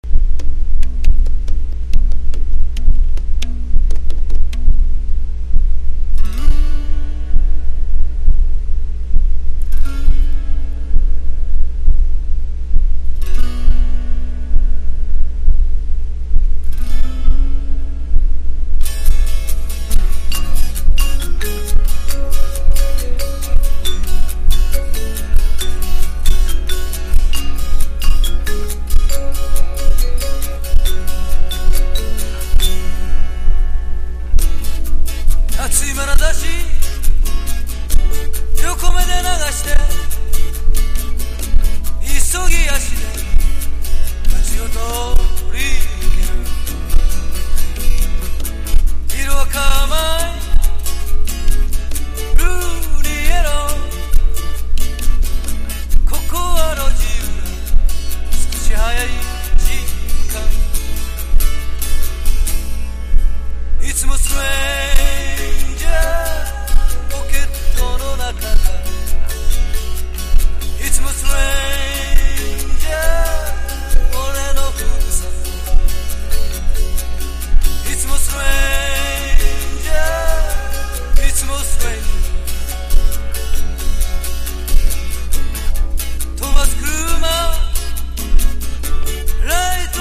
SSW / FOLK